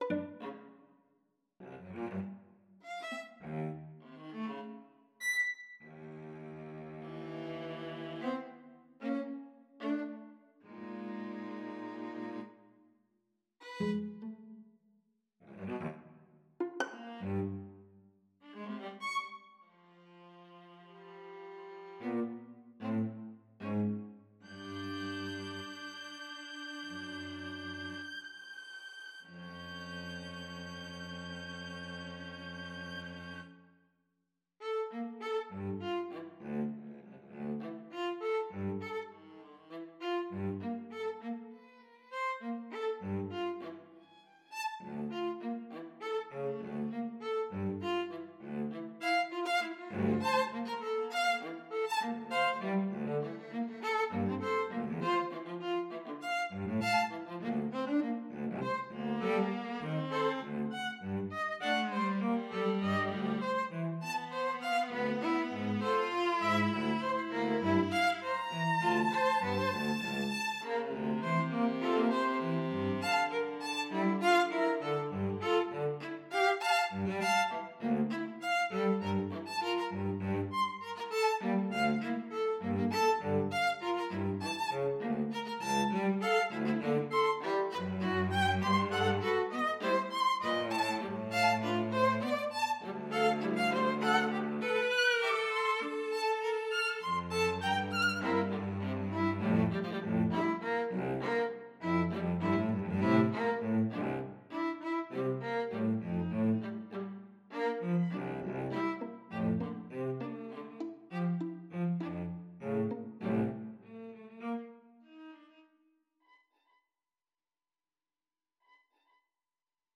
Kammermusik
The Hidden Fugue (für Streichtrio)